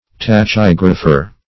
Search Result for " tachygrapher" : The Collaborative International Dictionary of English v.0.48: Tachygrapher \Ta*chyg"ra*pher\, n. [Gr.